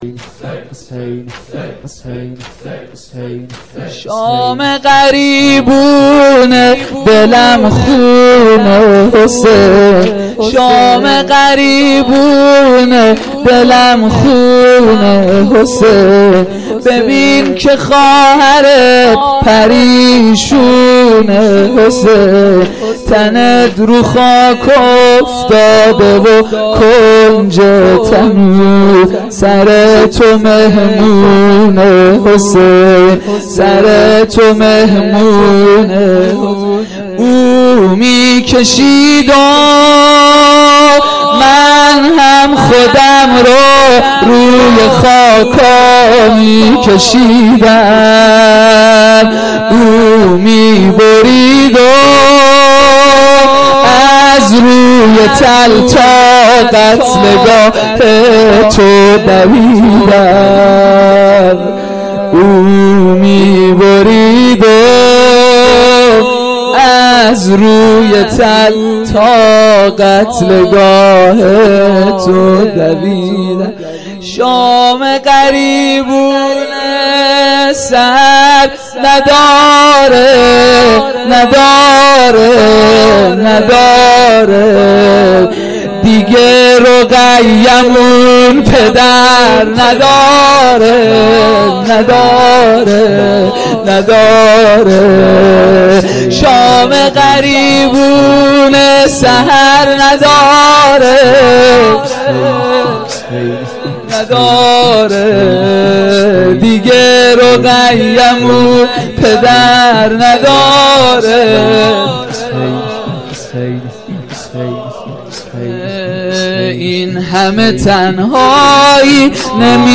جلسه مذهبی زیارت آل یاسین باغشهر اسلامیه
زمینه - شب شام غریبان محرم 95-03